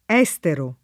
DOP: Dizionario di Ortografia e Pronunzia della lingua italiana
[ $S tero ]